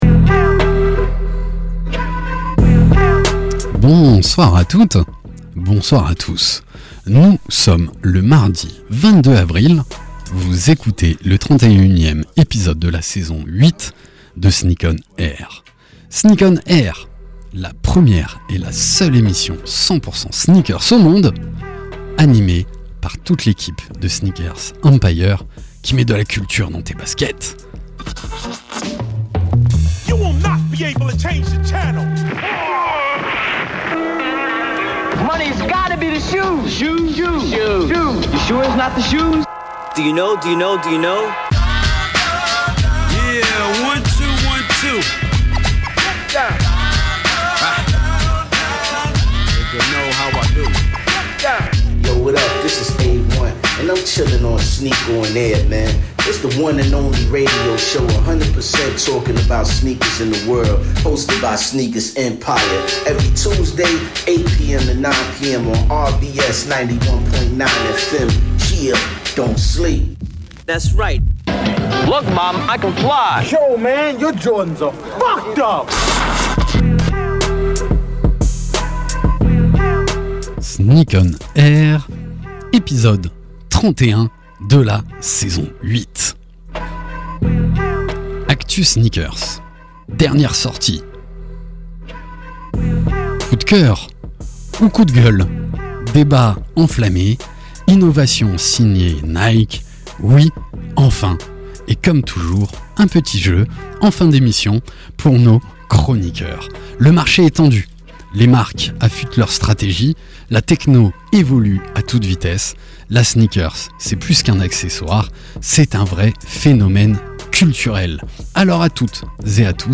Sneak ON AIR, la première et la seule émission de radio 100% sneakers au monde !!! sur la radio RBS tous les mardis de 20h à 21h.
Pour cet épisode, nous vous proposons une heure de talk, d’actus, et de débats autour des faits marquants de l’univers de la sneaker avec tous nos chroniqueurs.